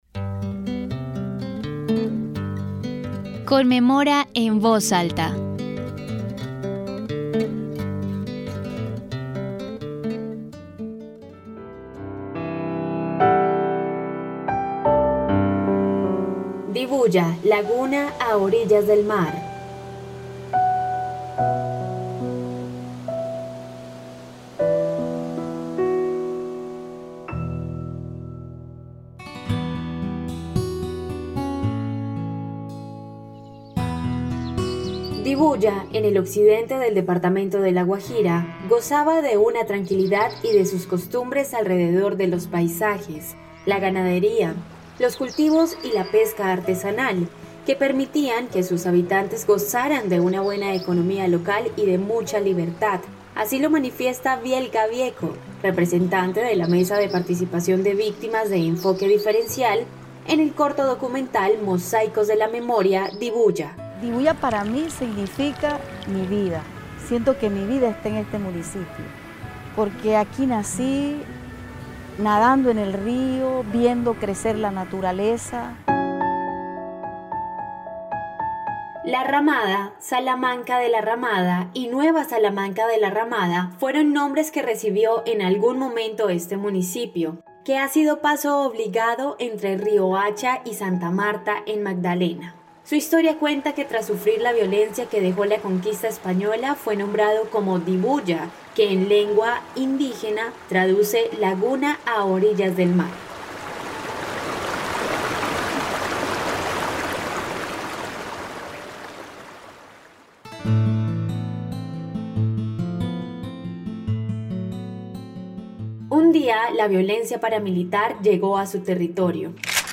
Capítulo número 12 de la tercera temporada de la serie radial "Conmemora en voz alta".